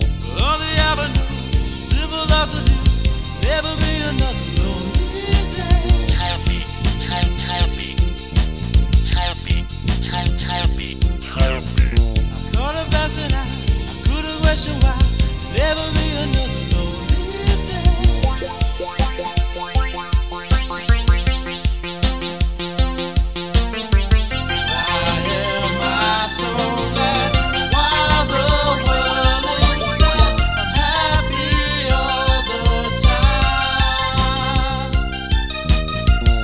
electronic music
sampled voice in the song.
keyboards, samplers